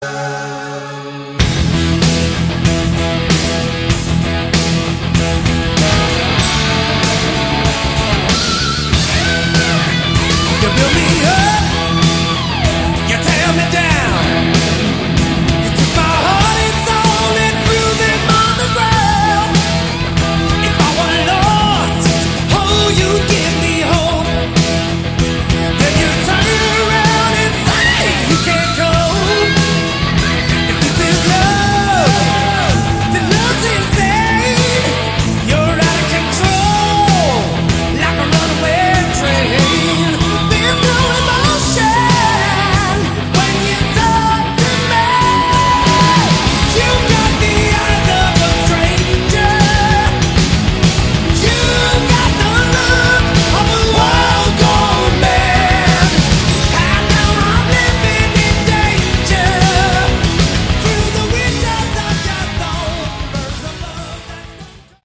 all guitars
lead vocals
bass
keyboards
drums